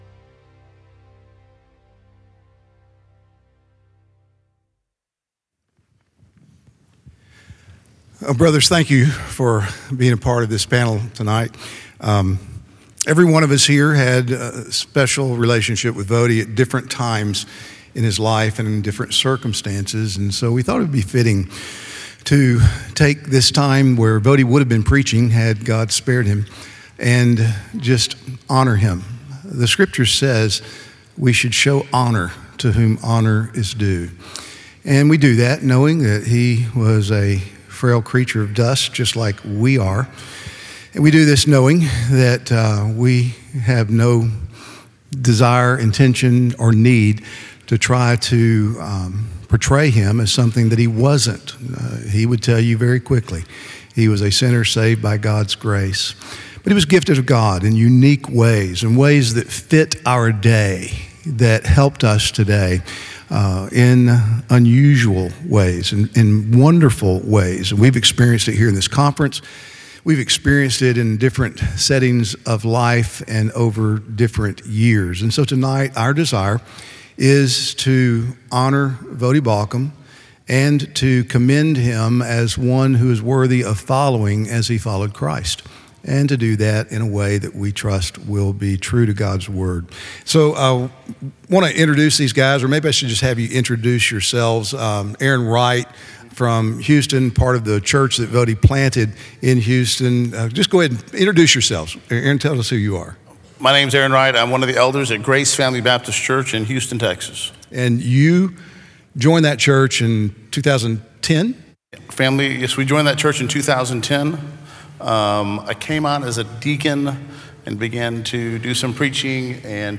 Remembering Voddie Baucham Panel